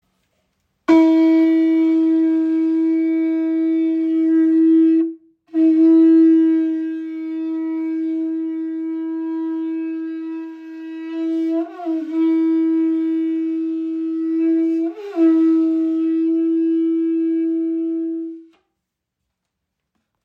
• Poliert: Sanftes Mundstück für komfortables Spielen bei kraftvollem, tragendem Klang.
Kuhhorn - Signalhorn poliert Nr. 2